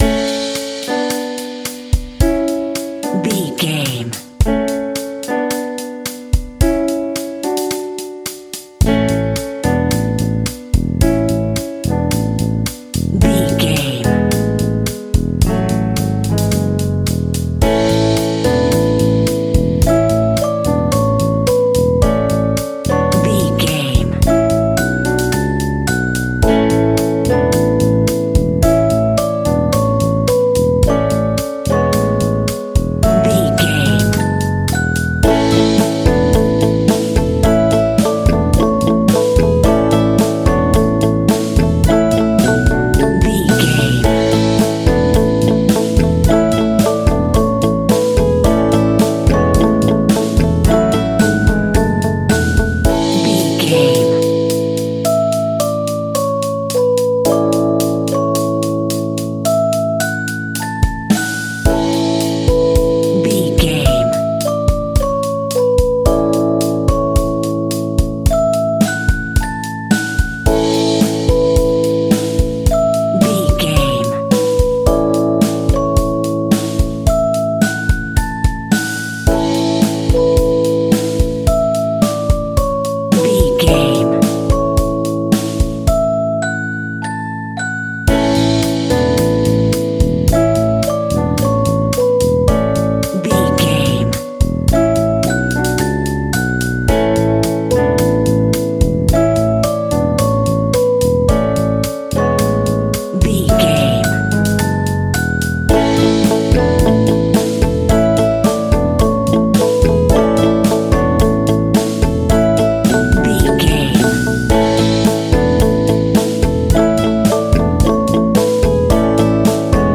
Ionian/Major
nursery rhymes
childrens music
drums
bass guitar
electric guitar
piano
hammond organ